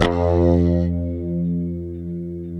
F#2 HSTRT VB.wav